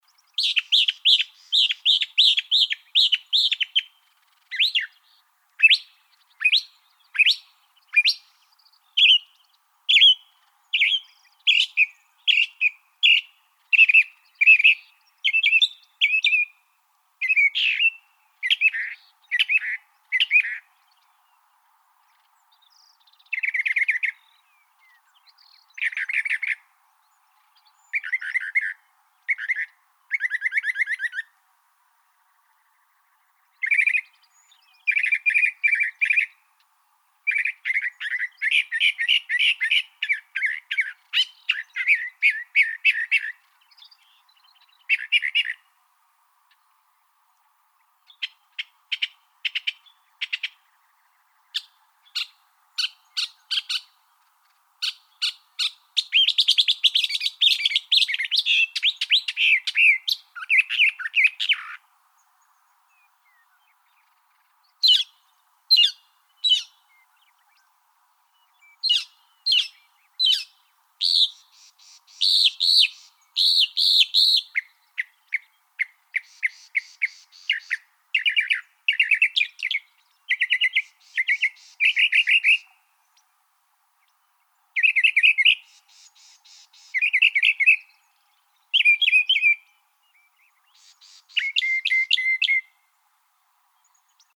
northernmockingbird.wav